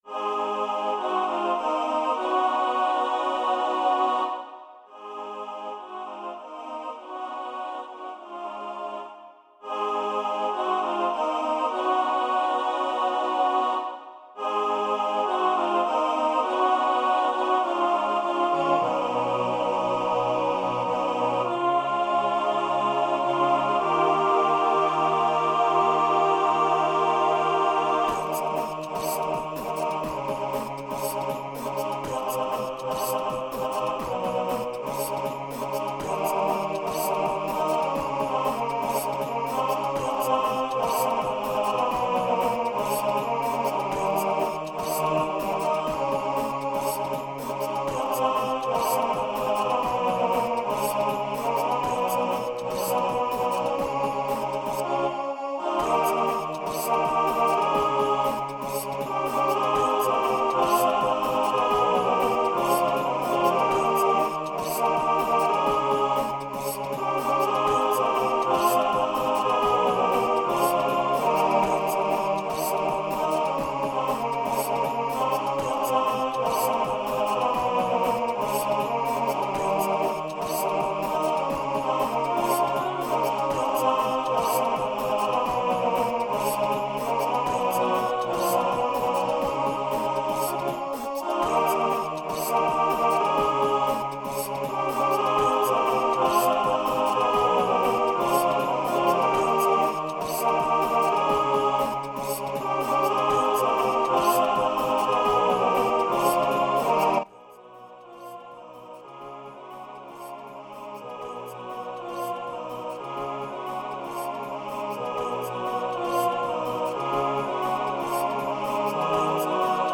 (a cappella with instrumental dance break and ending)
Voicing TTB
Genre Rock